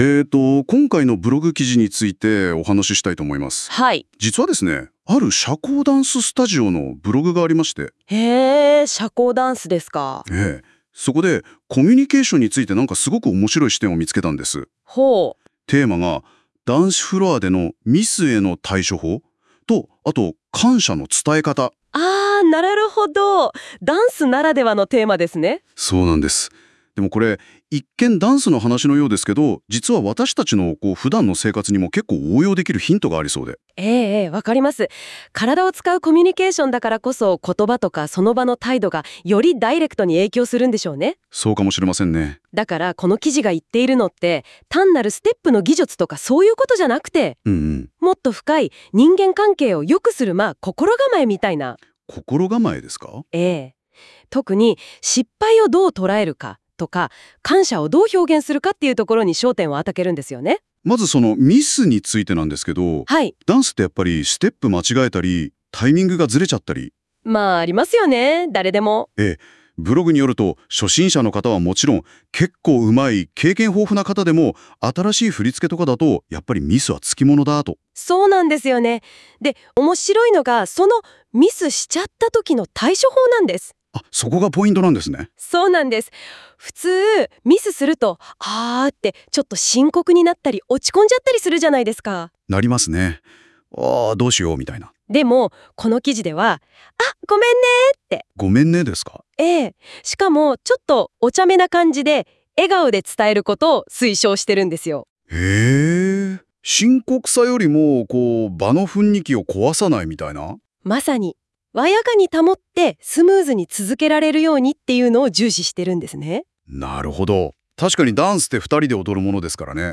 このブログについて、AIで生成した会話音声（約5分）も、お楽しみいただけます。